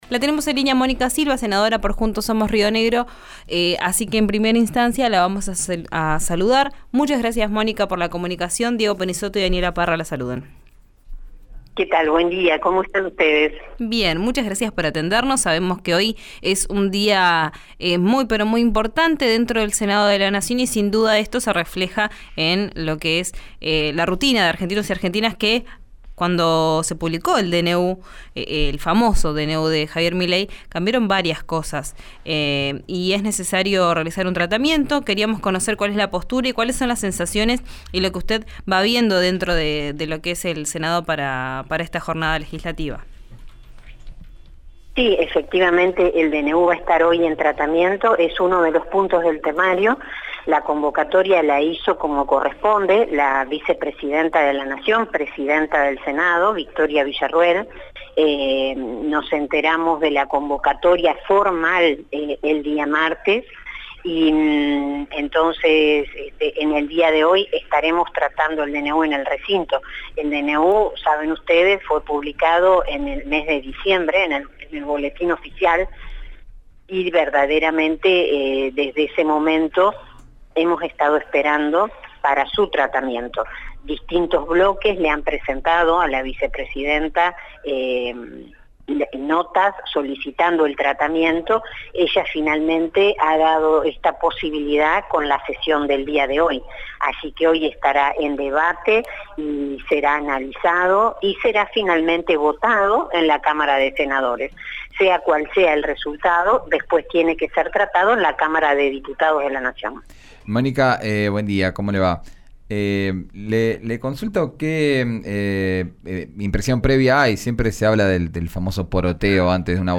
Escuchá a Mónica Silva en RÍO NEGRO RADIO: